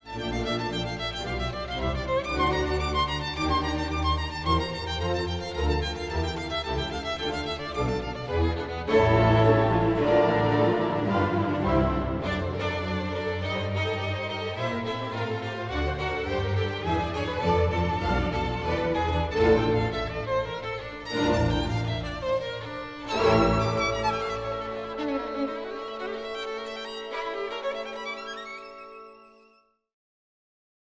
Concerto-violon-Brahms-30-sec.mp3